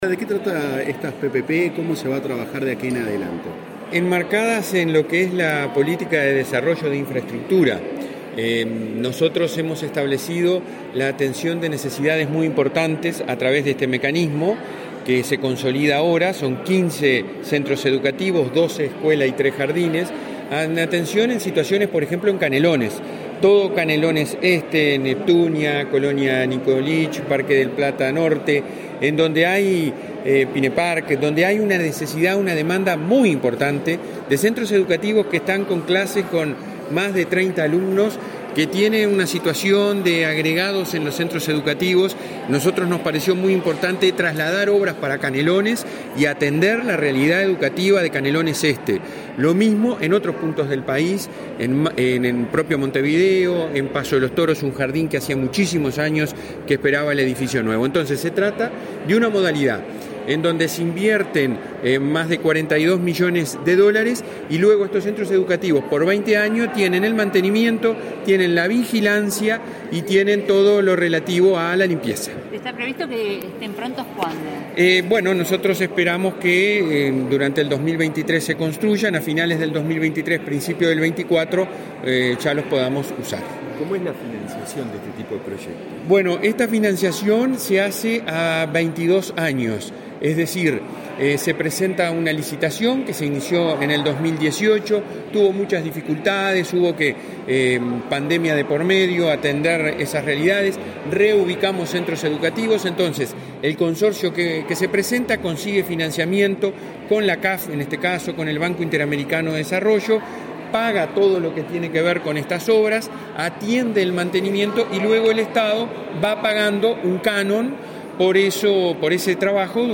Declaraciones del presidente del Codicen de la ANEP, Robert Silva
El presidente del Consejo Directivo Central (Codicen) de la Administración Nacional de Educación Pública (ANEP), Robert Silva, dialogó con periodistas